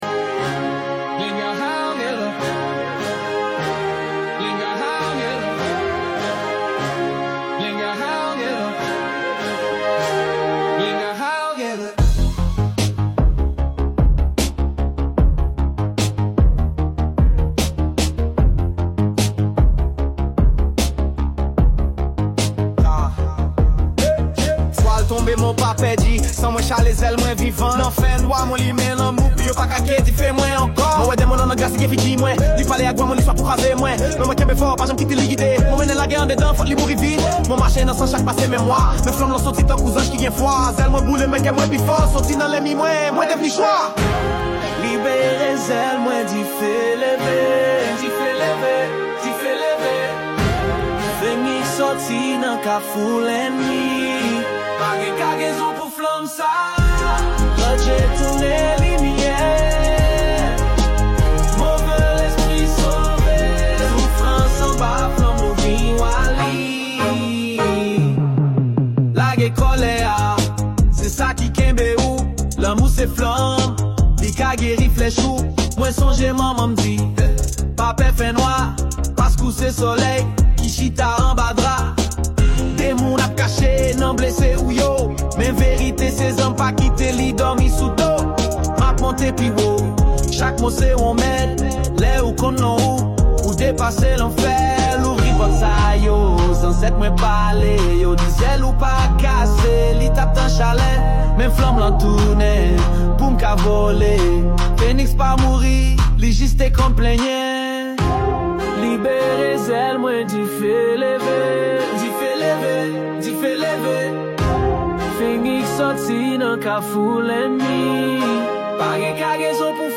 Intro – Ancestral Chant, whispers
Verse 1 – Rap Flow
Chorus – Choir, Call-and-Response Style